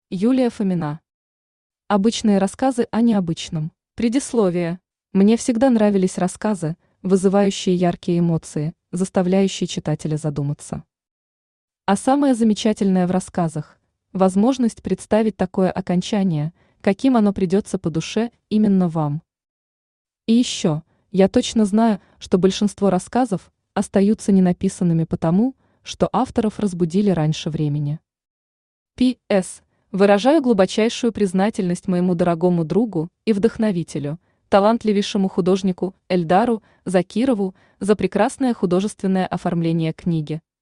Аудиокнига Обычные рассказы о необычном | Библиотека аудиокниг
Aудиокнига Обычные рассказы о необычном Автор Юлия Александровна Фомина Читает аудиокнигу Авточтец ЛитРес.